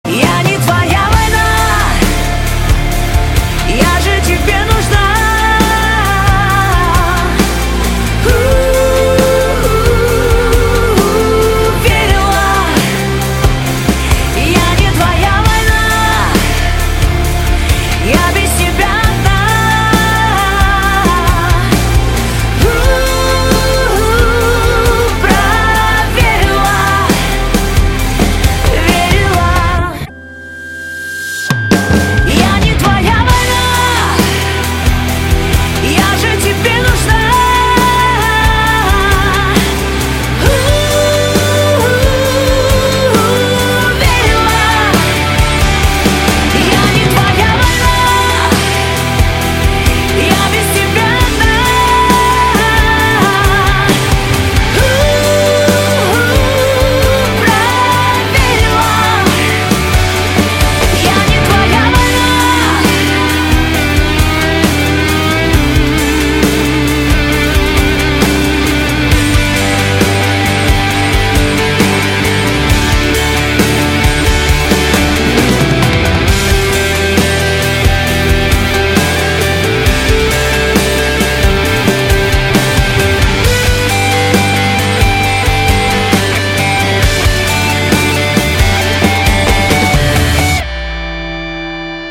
поп
громкие
женский вокал
романтичные
поп-рок
эстрадные